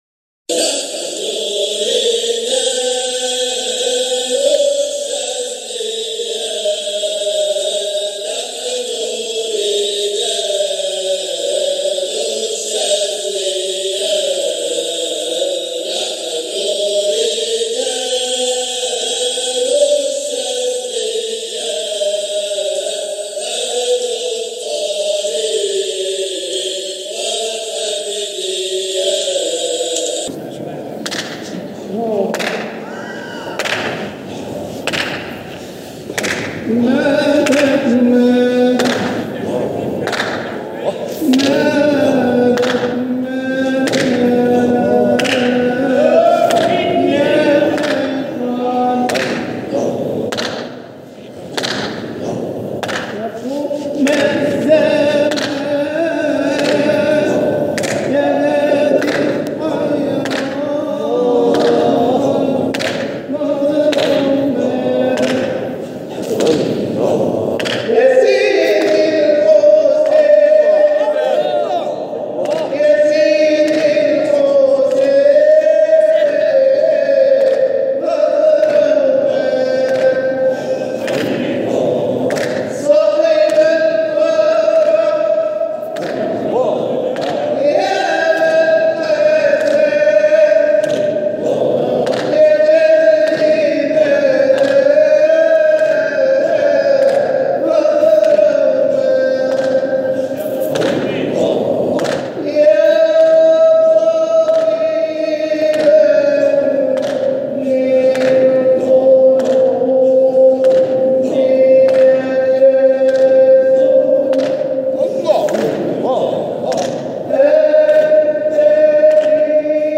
مقاطع من احتفالات ابناء الطريقة الحامدية الشاذلية بمناسباتهم
جزء من حلقة ذكر بمسجد سيدنا ابى الحسن الشاذلى قدس سره 2019